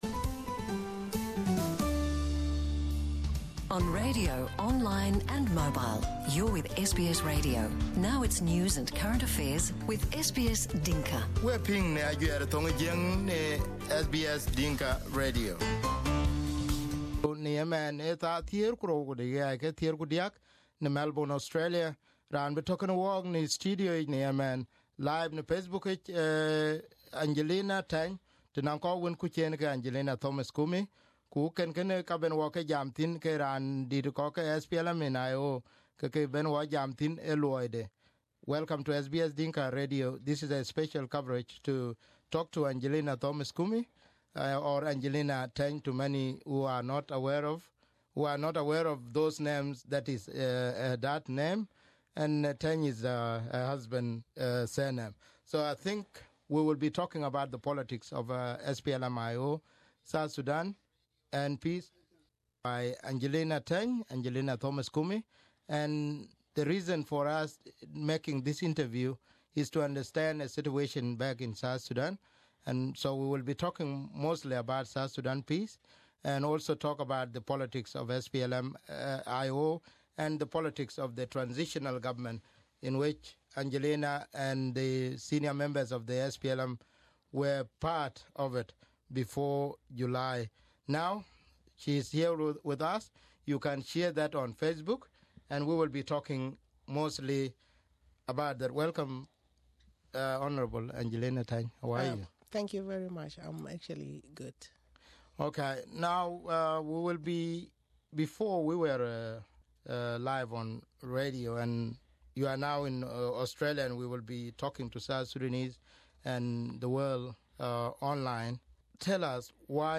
Agenlian in SBS Studio Source